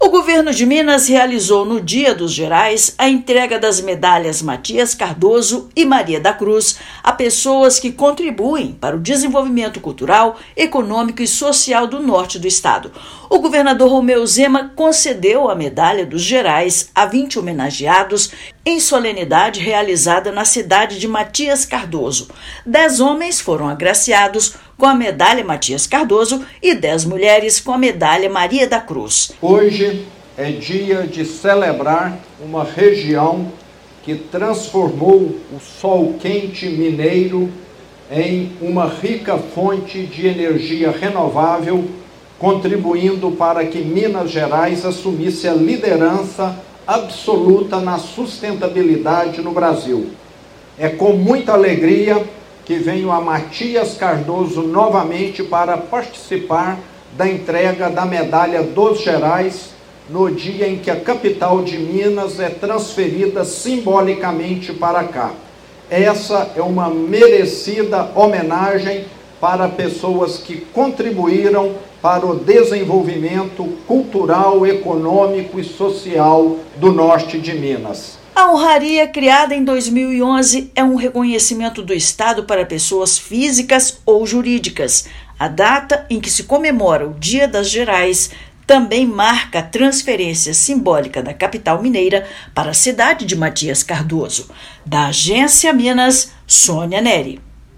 No Dia dos Gerais, domingo (8/12), capital de Minas é transferida simbolicamente para a cidade de Matias Cardoso, onde as honrarias são entregues. Ouça matéria de rádio.